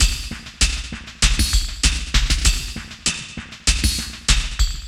98WAGONLP2-L.wav